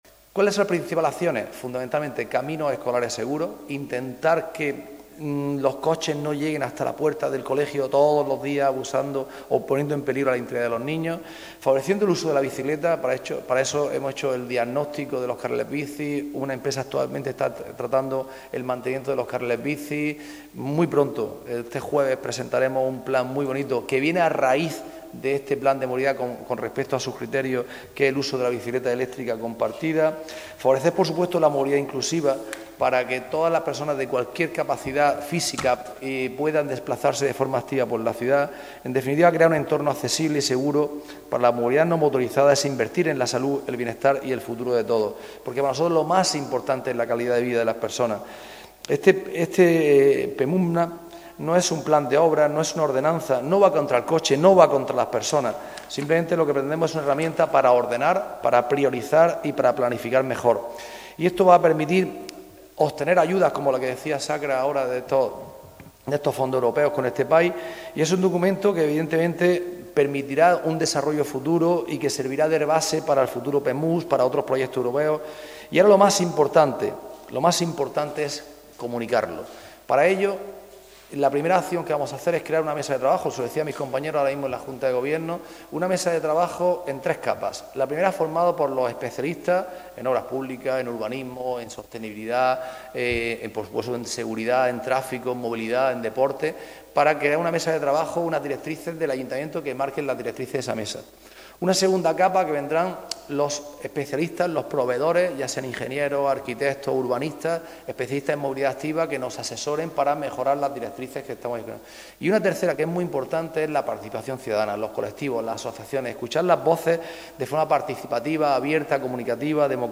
Ese es el propósito del Plan de Movilidad Urbana No Motorizada y Activa (PMUNMA) para el ámbito del Centro Histórico, presentado hoy, en rueda de prensa, por el concejal de Ciudad Activa, Movilidad Urbana y Deporte del Ayuntamiento de Almería, Antonio Casimiro, acompañado por la concejala de Obras Públicas, Mantenimiento, Accesibilidad y Economía Azul, Sacramento Sánchez.